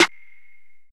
Snare + Perc(1).wav